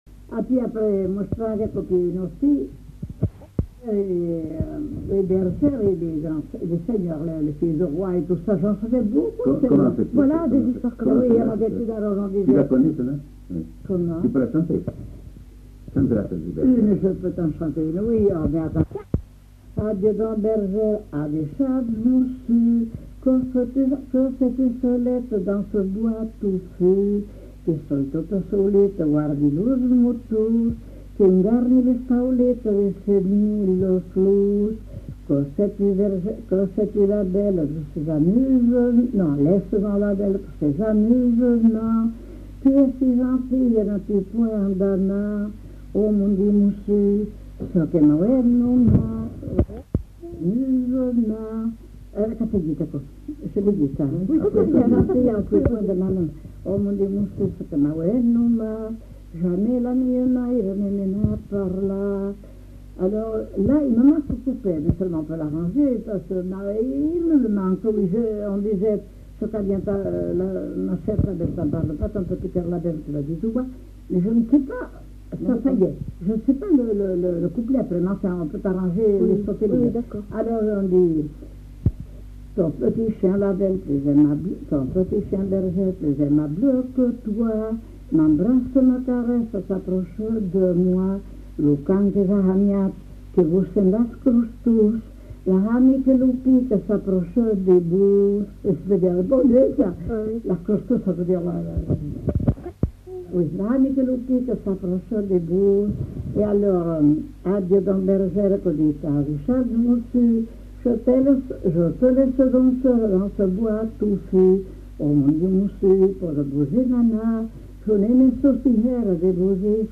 Aire culturelle : Marsan
Genre : chant
Effectif : 1
Type de voix : voix de femme
Production du son : chanté
Classification : bergères et monsieur (dialogue français-patois)